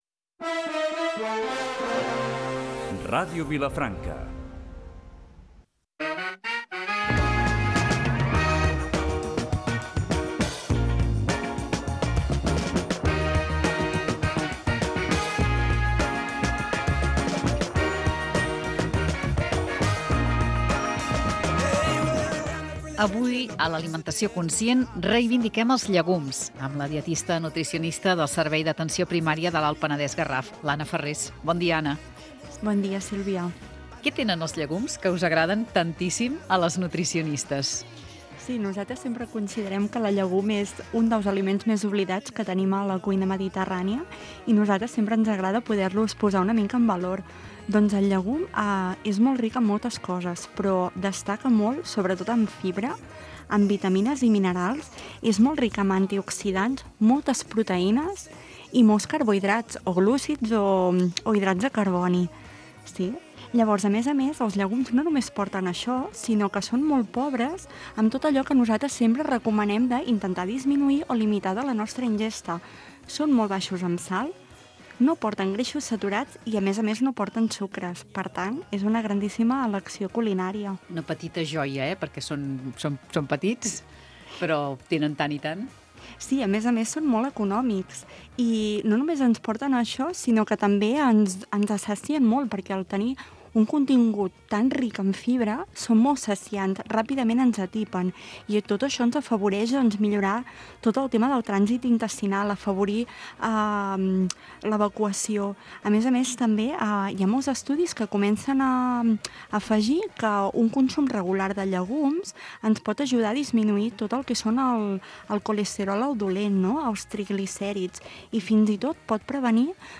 Magazín matinal